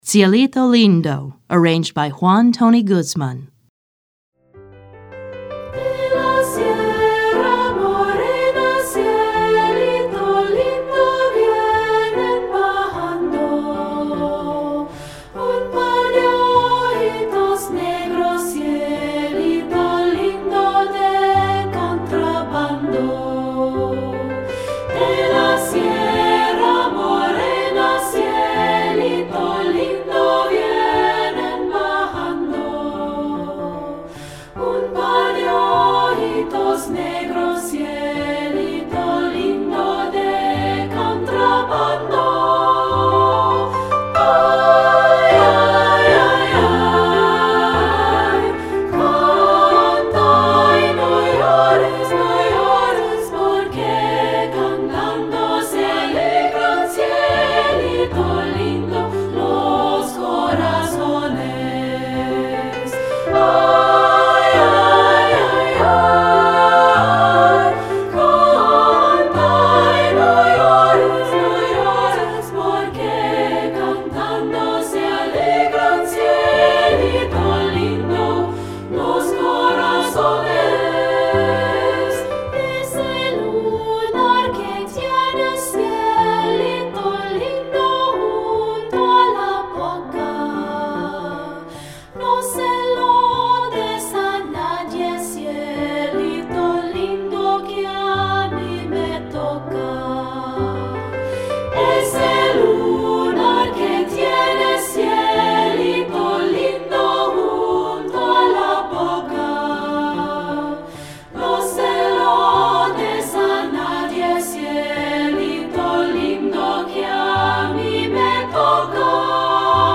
Mexican Folk Song